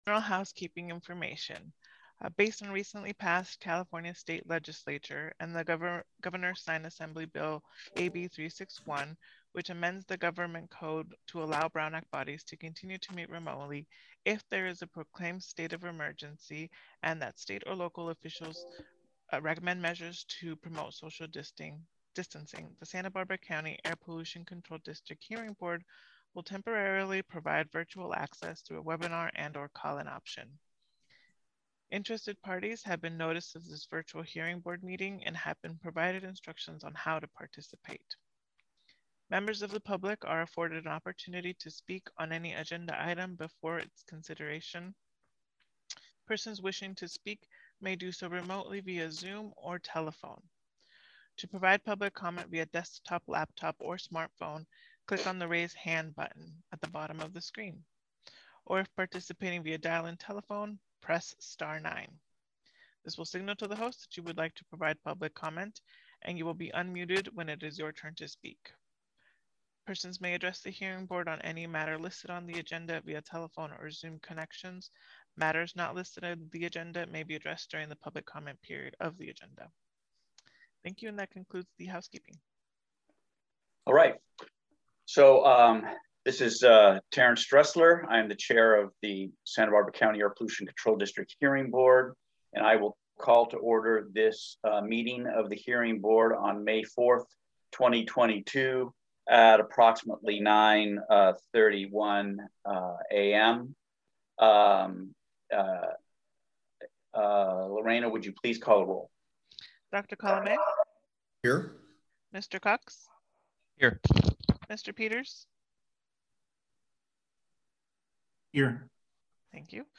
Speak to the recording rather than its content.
*REMOTE VIRTUAL PARTICIPATION ONLY